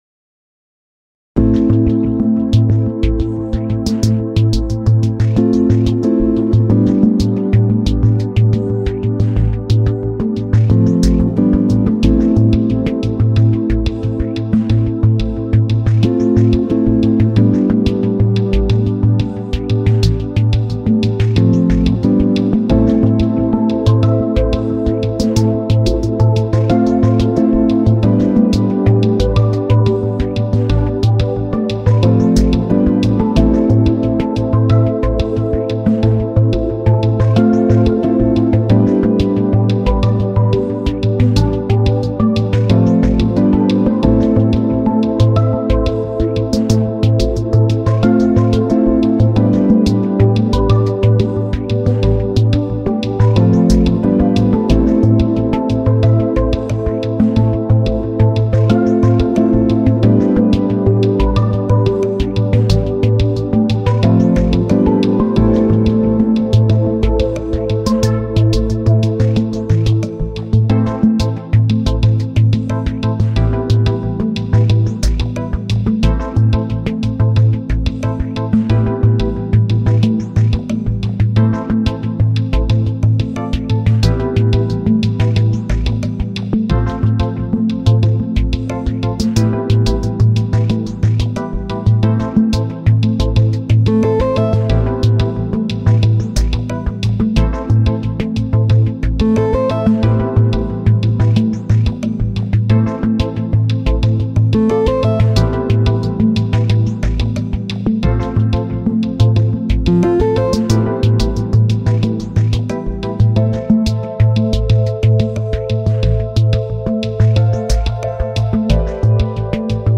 rock - melodique - planant - culte - efficace